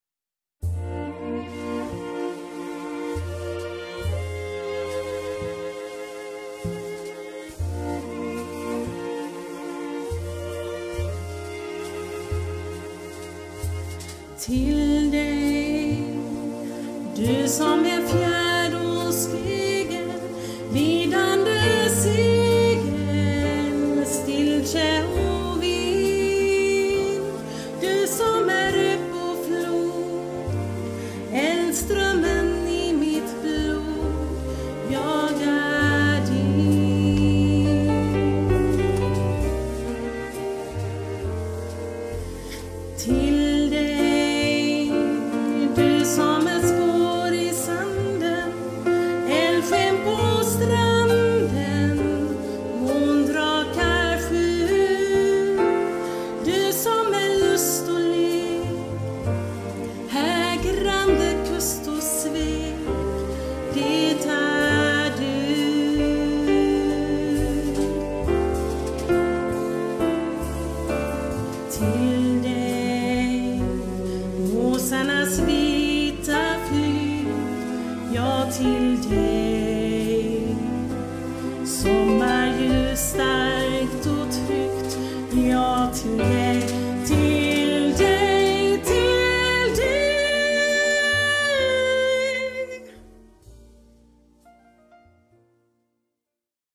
Här kan du lyssna på några exempel på låtar, men givetvis sjunger jag låtarna ni vill höra på er dag.